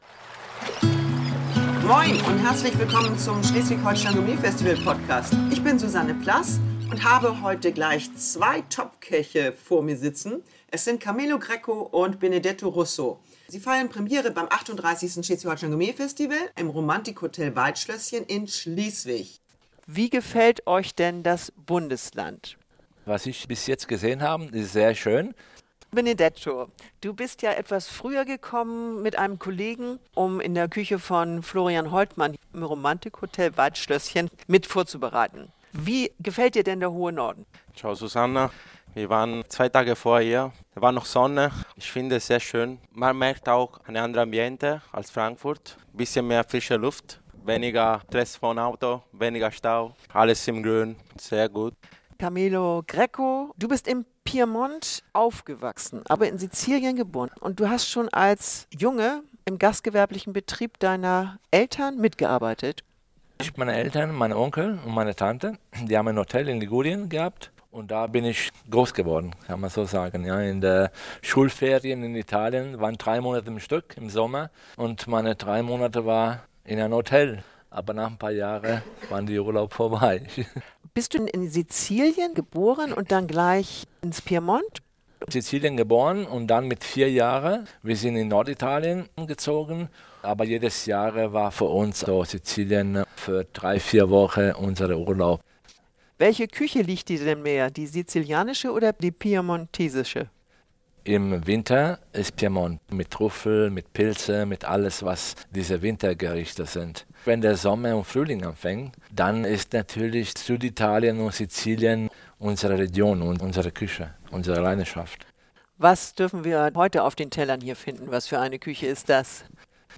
Der Podcast vom Schleswig-Holstein Gourmet Festival stellt die Gastköchinnen und Gastköche sowie die Gastgeber, Mitgliedshäuser und Partner in bunter Reihenfolge vor. Durch die lockeren Gespräche erfahren die Hörer mehr über die Persönlichkeiten, deren Gerichte, Produkte und Küchenstile.